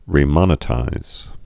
(rē-mŏnĭ-tīz, -mŭn-)